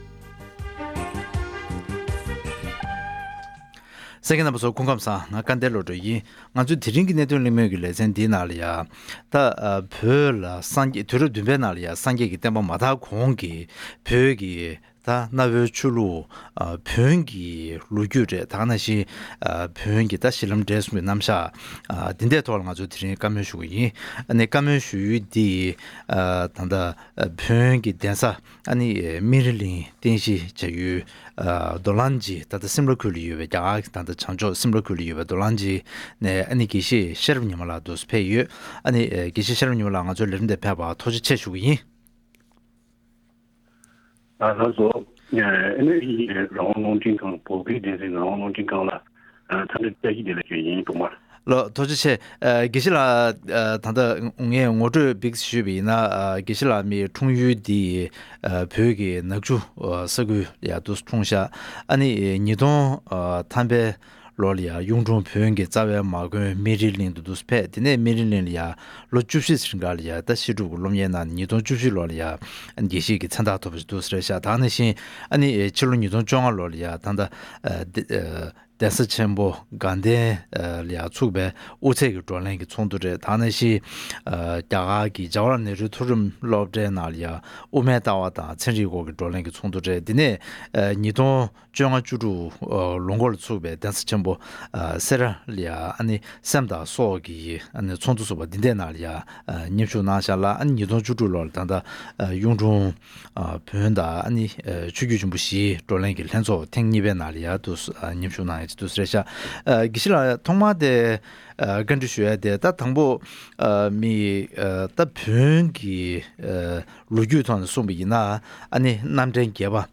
བོད་ནང་ལ་སངས་རྒྱས་གྱི་བསྟན་པ་མ་དར་གོང་གི་བོན་ཆོས་ལུགས་ཀྱི་ལོས་རྒྱུས་སོགས་དང་འབྲེལ་བའི་སྐོར་གླེང་མོལ།